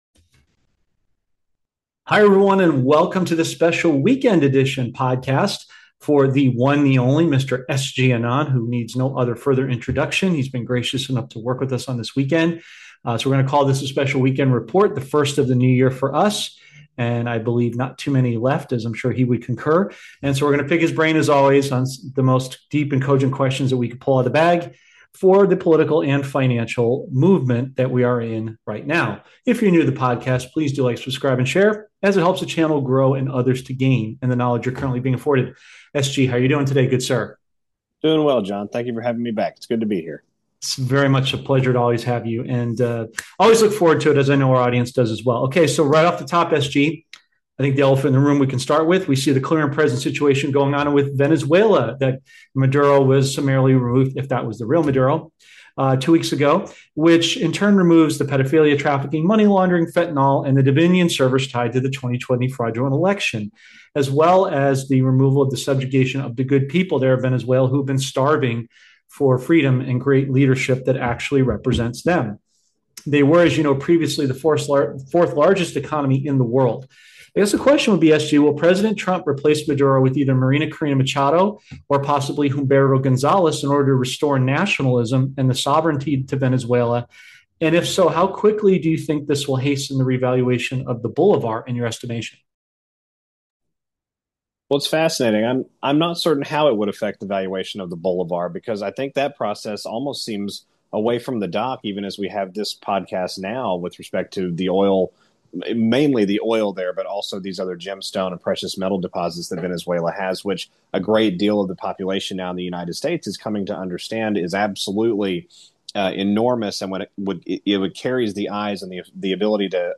This podcast features a discussion